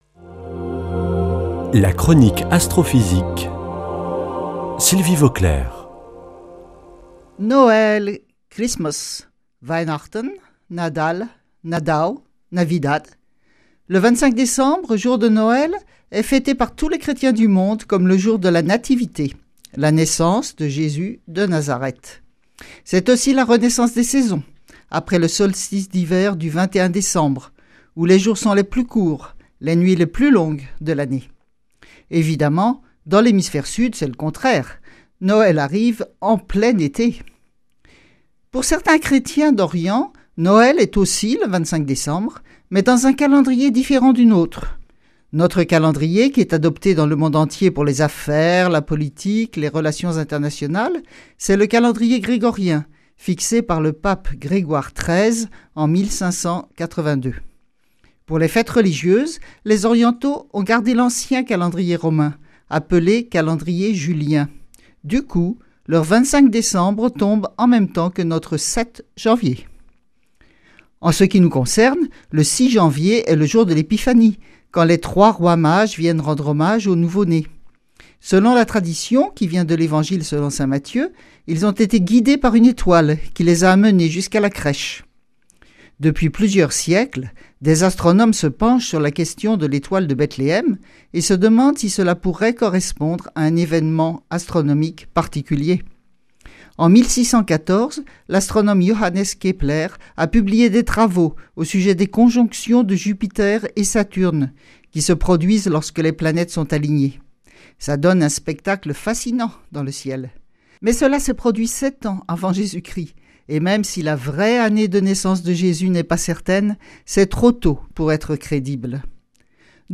Une émission présentée par
Sylvie Vauclair
Astrophysicienne